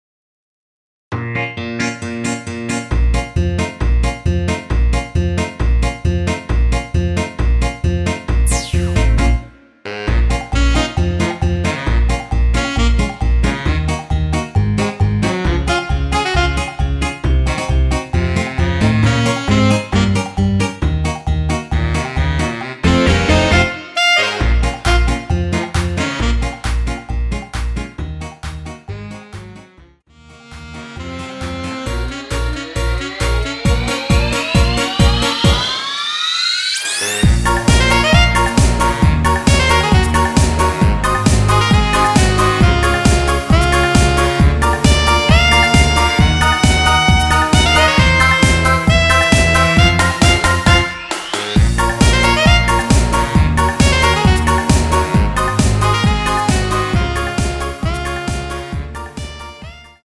원키에서(-8)내린 MR입니다.
Bbm
앞부분30초, 뒷부분30초씩 편집해서 올려 드리고 있습니다.